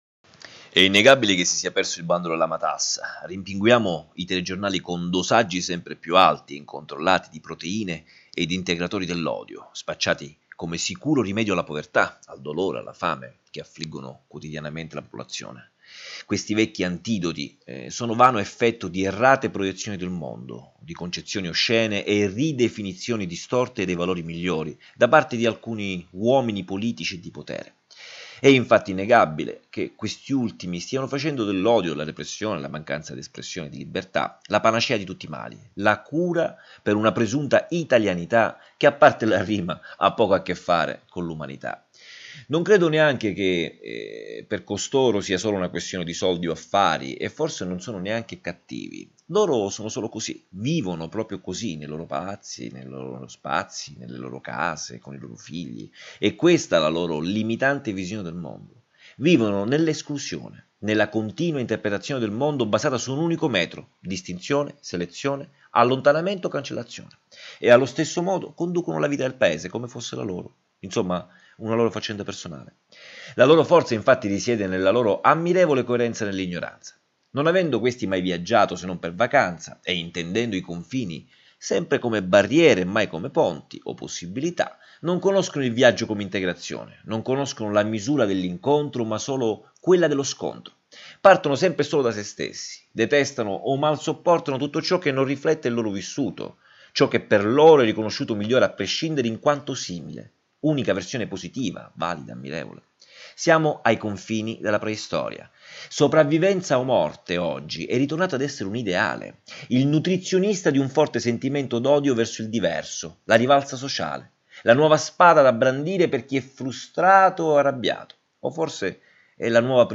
I minuti dei file audio riproducono le riflessioni degli articoli a voce alta, perché abbiano accesso all’ascolto i ciechi e quelli tra noi che pur avendo la vista sono diventati non vedenti,